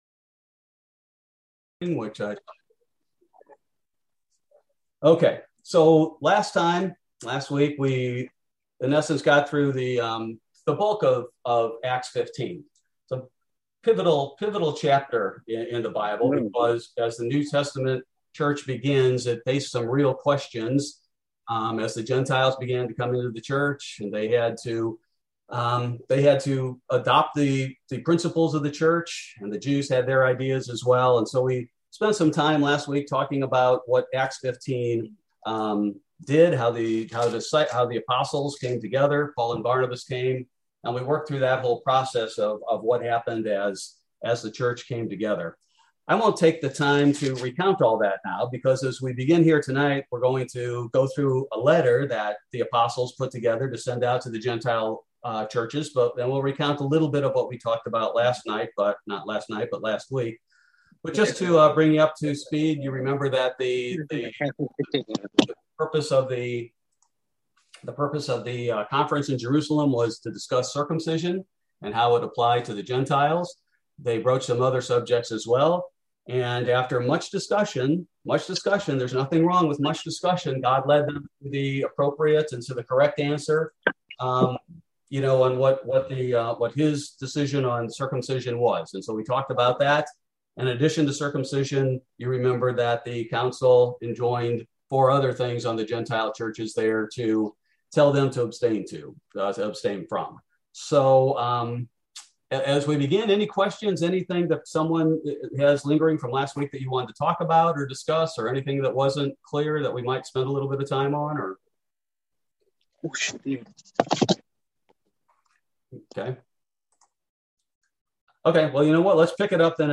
Bible Study: October 13, 2021